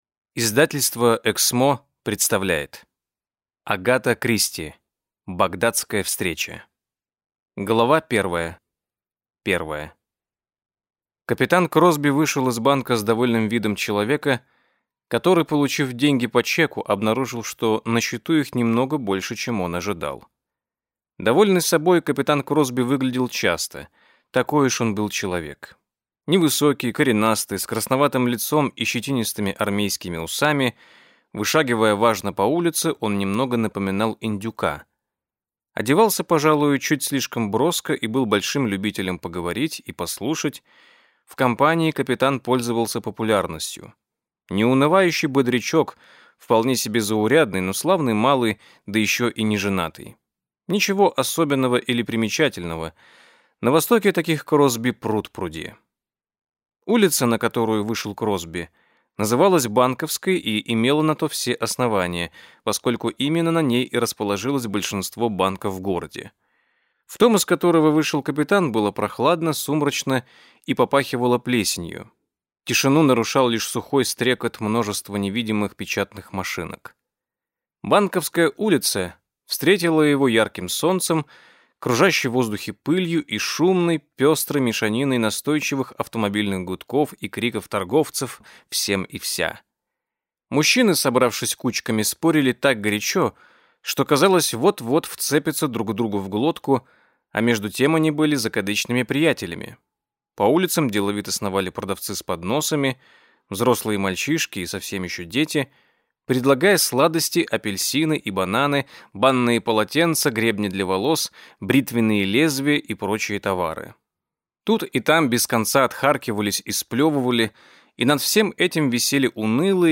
Аудиокнига Багдадская встреча - купить, скачать и слушать онлайн | КнигоПоиск